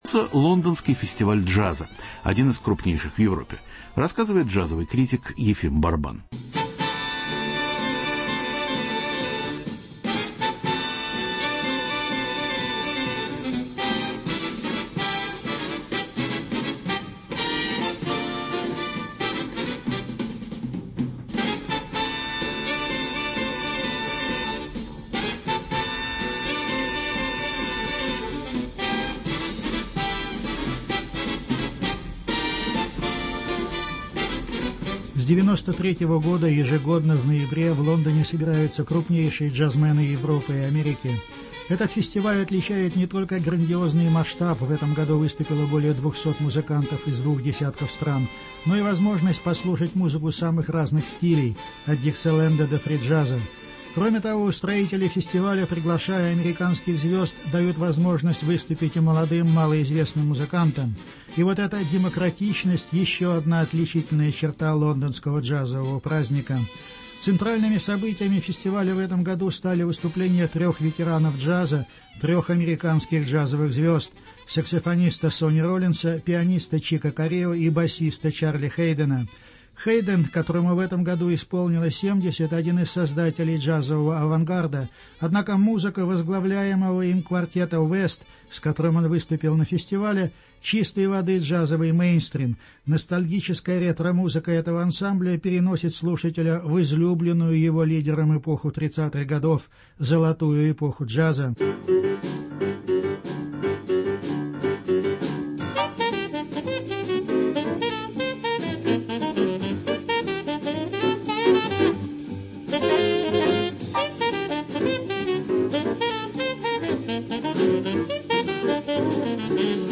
Джаз-фестиваль в Лондоне, интервью с Чик Кориа.